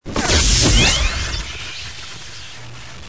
Молнии: